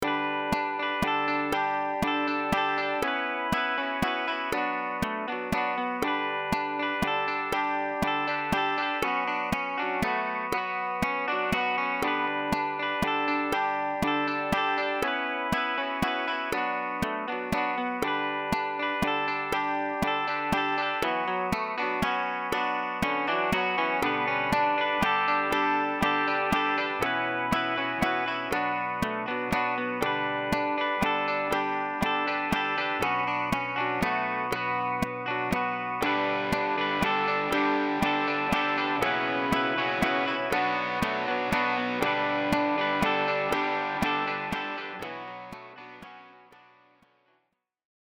Recorded At MWEMUSIC Little Birds Productions